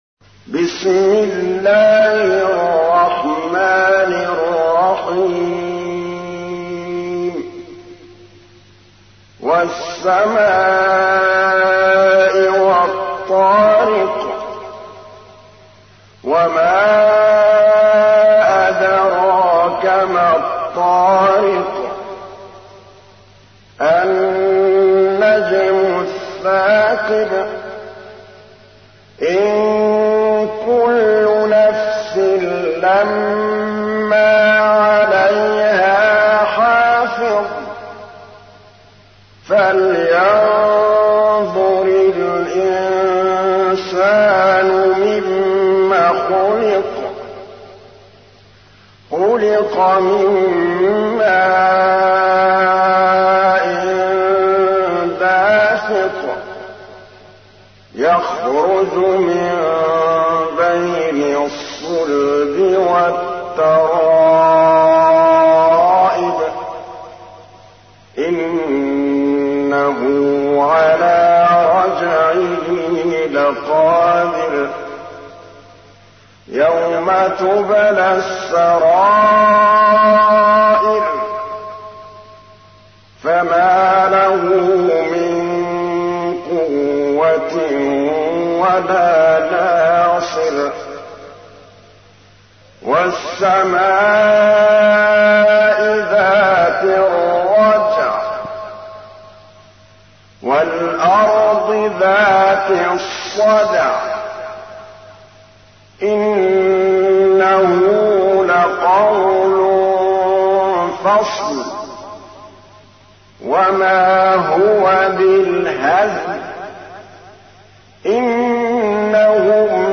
تحميل : 86. سورة الطارق / القارئ محمود الطبلاوي / القرآن الكريم / موقع يا حسين